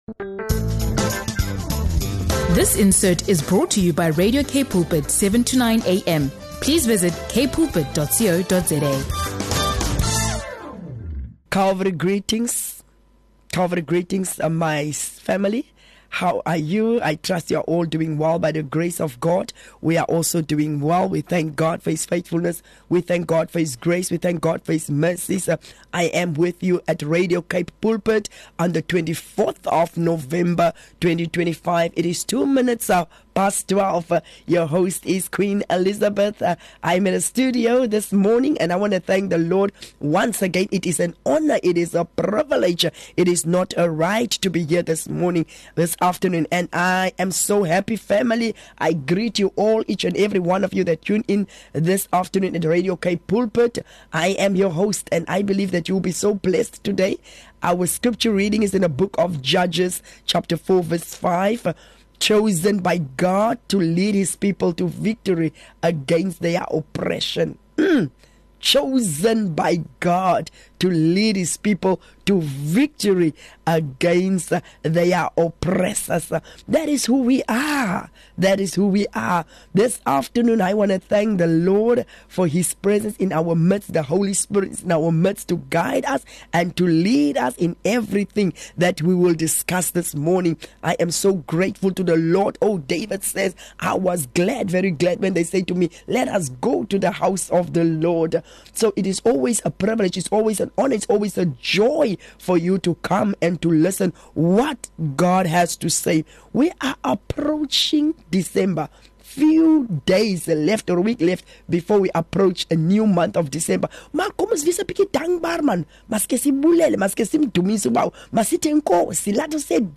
a powerful conversation